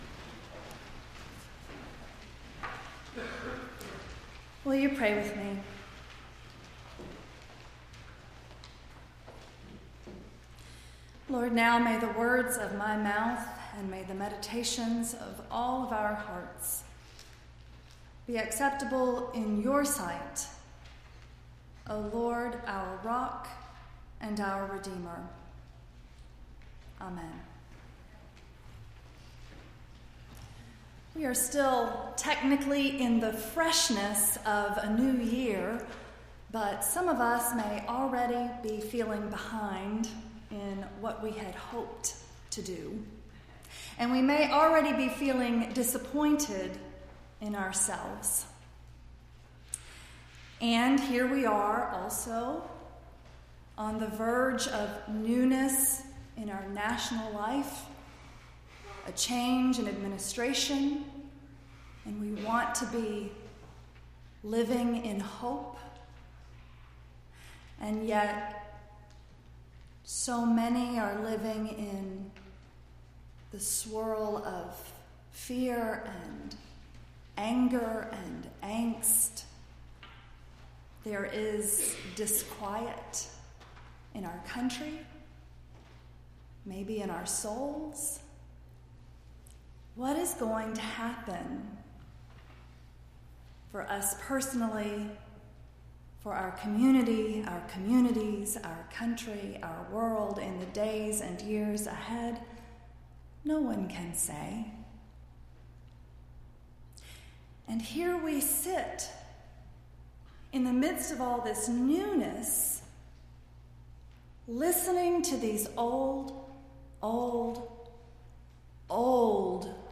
1-15-17-sermon.mp3